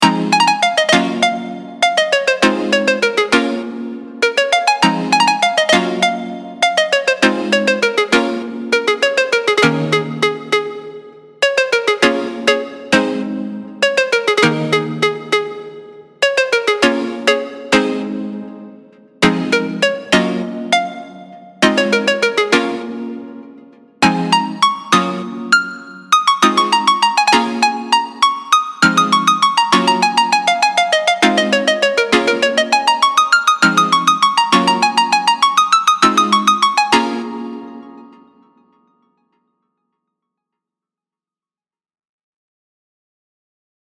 Dark/Sad